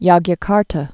(yŏgyə-kärtə, jôkjä-, jŏkyə-) or Jog·ja·kar·ta (jŏgyə-, jôkjä-)